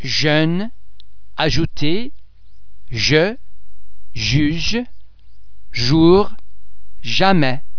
Please be mindful of the fact that all the French sounds are produced with greater facial, throat and other phonatory muscle tension than any English sound.
The French letter [j] is normally pronounced as the [s] in the English words treasure, pleasure etc.
j_jeune.mp3